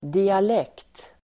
Ladda ner uttalet
Uttal: [dial'ek:t]